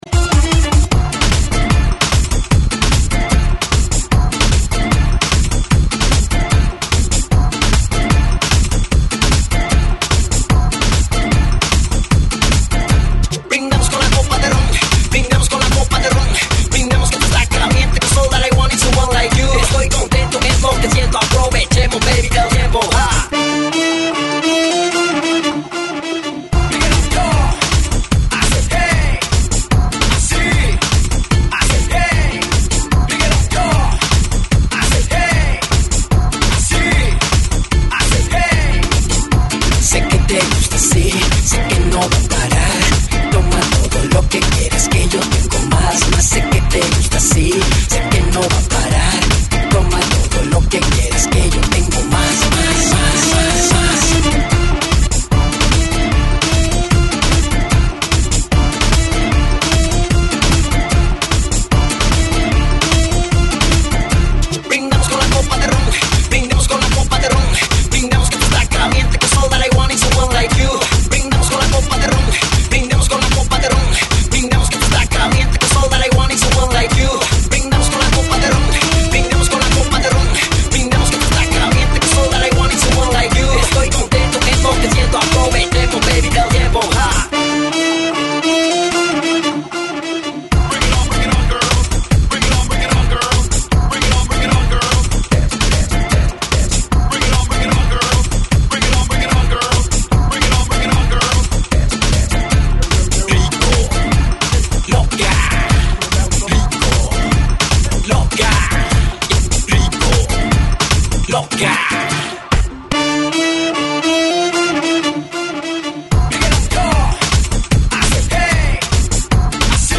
GENERO: REMIX LATINO